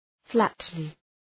{‘flætlı}
flatly.mp3